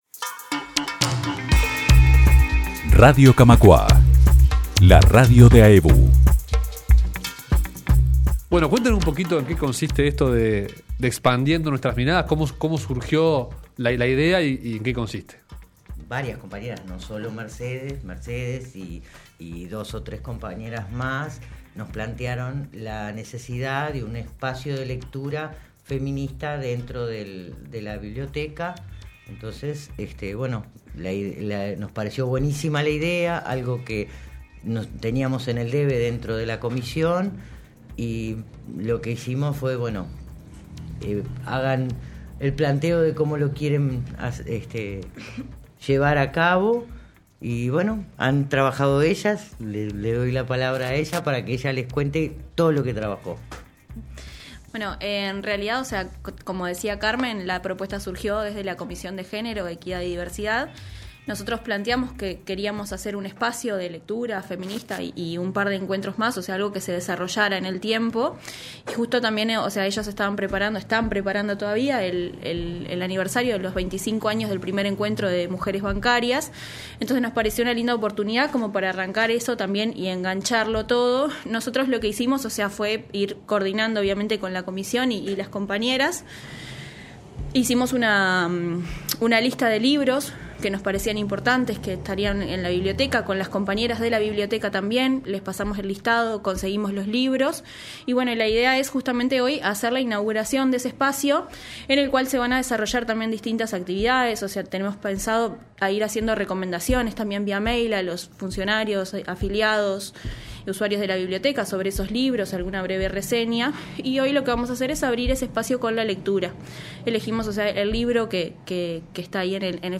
En Camacuá y Reconquista conversamos con dos integrantes de la Comisión